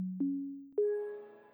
Windows 11, the latest version of Windows to date, brings a new startup sound that’s known to be very calming and positive.
Windows 11 Startup Sound